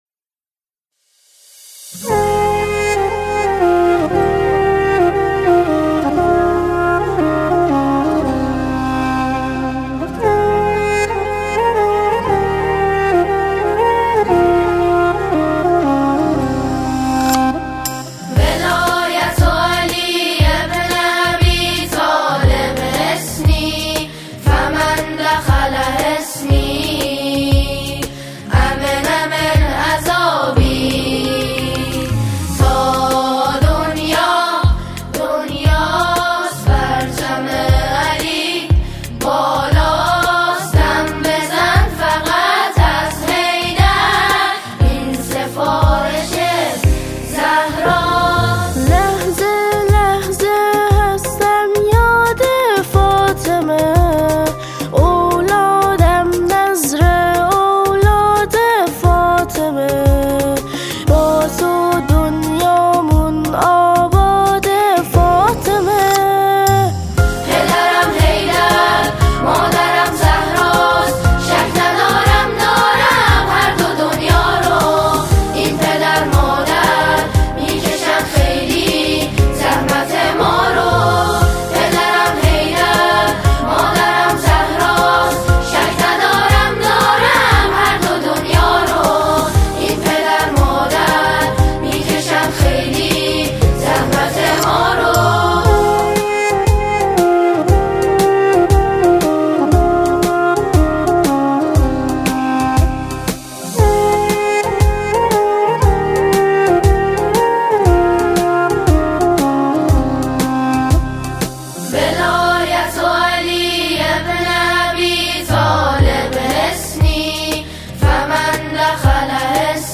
سرودی زیبا که موضوع های مختلفی در آن گنجانده شده است.